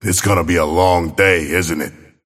Abrams voice line - It's gonna be a long day, isn't it?